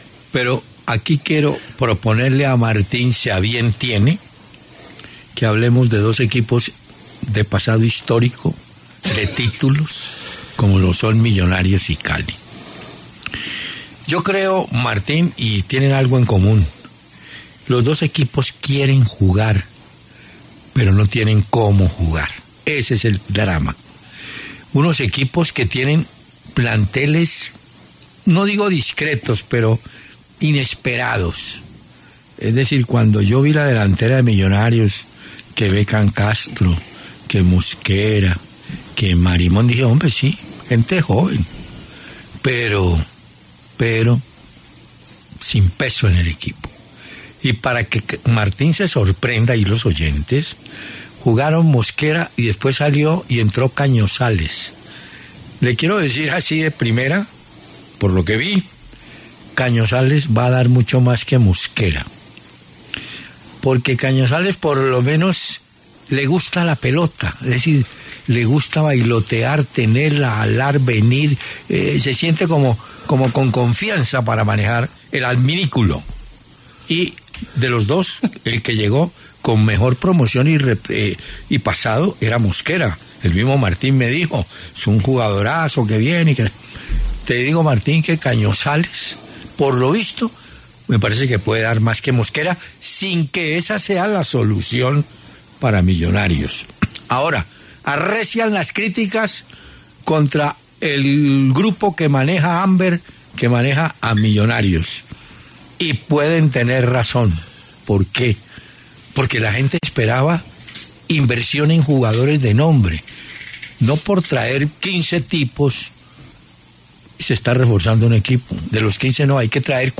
Hernán Peláez y Martín de Francisco analizaron la situación deportiva de Millonarios, principalmente, el cual encadena tres partidos por liga seguidos sin ganar y sin marcar gol; el último contra Medellín.